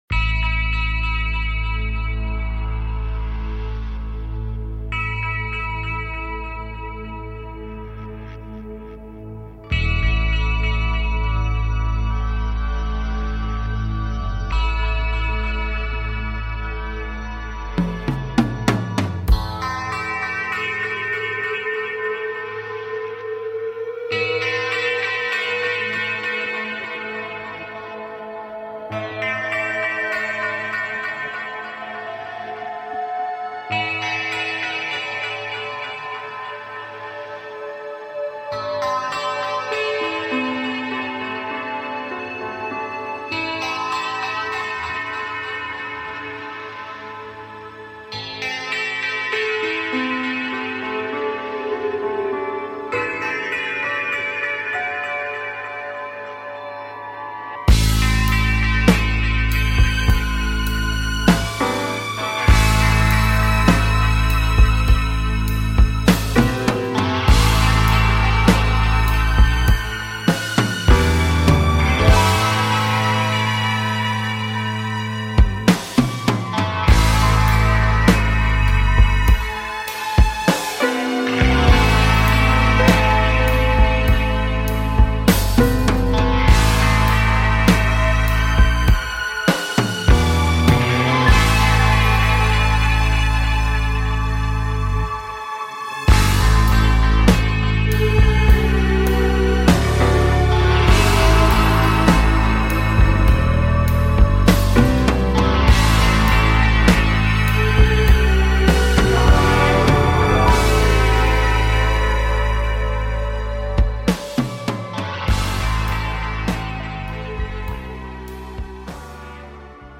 Talk Show Episode, Audio Podcast, PRQ TIMELINES with Q Friends and with Q Friends on , show guests , about There Aren't Any Surprises Here,Epstein List Names,This Is Why Trump Wants You To Move On,The Epstein List, categorized as Earth & Space,Entertainment,Paranormal,Physics & Metaphysics,Society and Culture,Spiritual,Access Consciousness,Variety